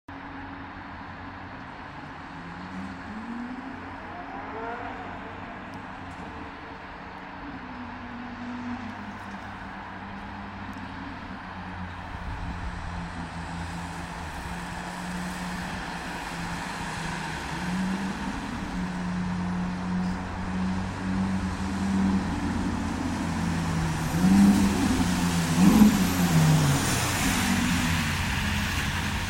❤Ferrari 458 Leaves The Ace Sound Effects Free Download